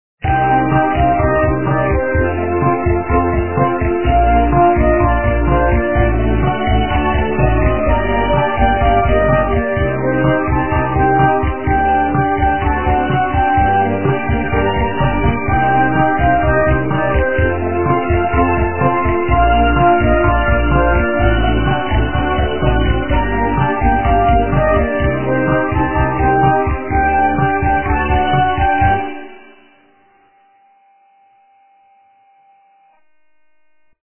качество понижено и присутствуют гудки